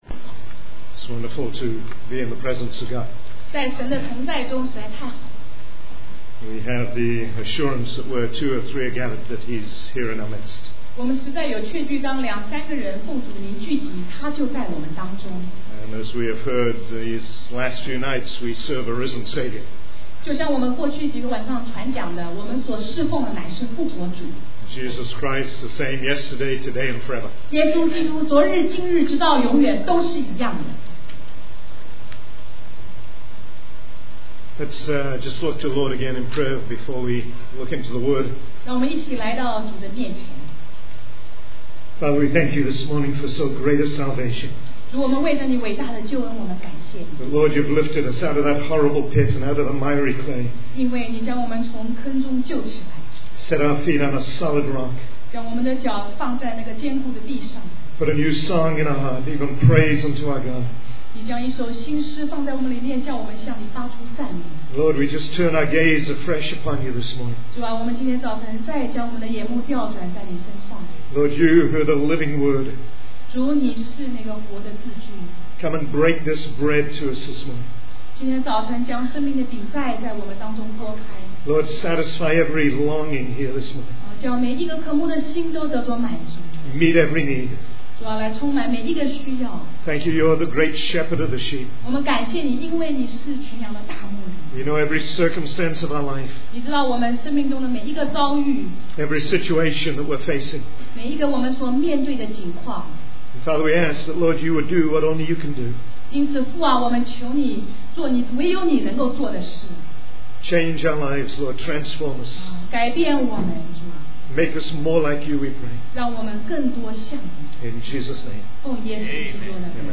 In this sermon, the speaker tells a story about a woman who experiences different emotions throughout her life.